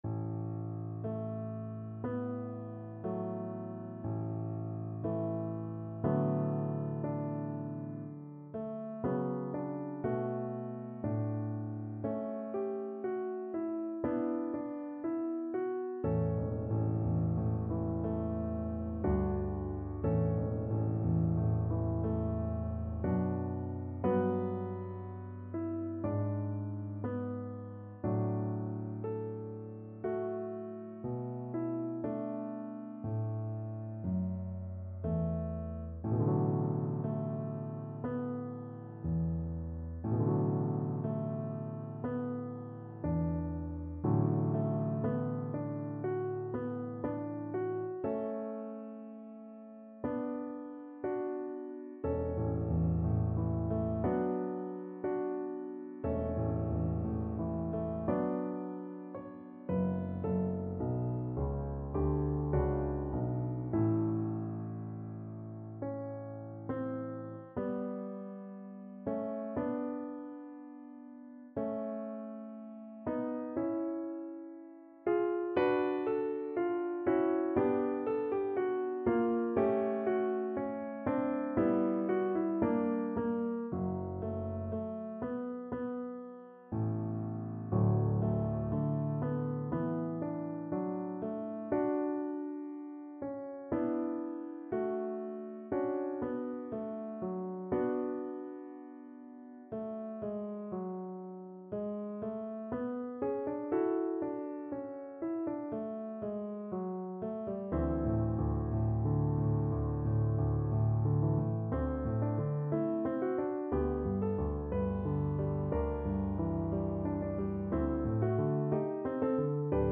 = 60 Molto lento e ritenuto
4/4 (View more 4/4 Music)
Classical (View more Classical Cello Music)